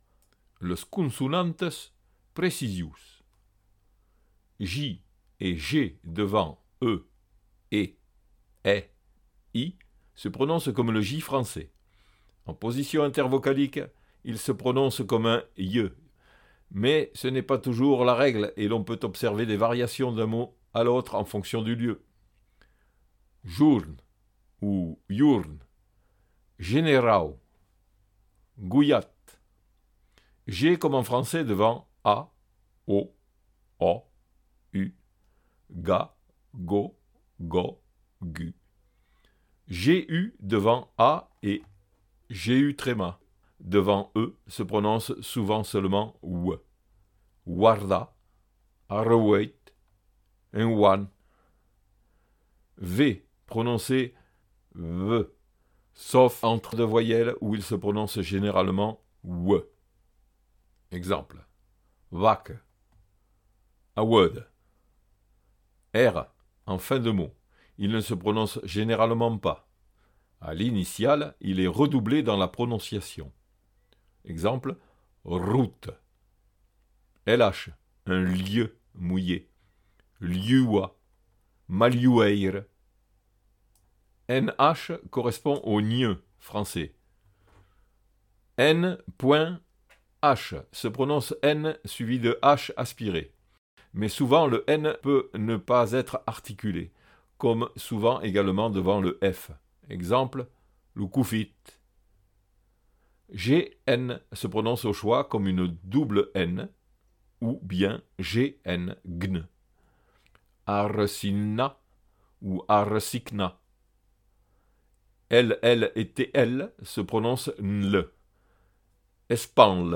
des voyelles et consonnes en gascon maritime
Fichier Son : Las consonantas maritimas precisions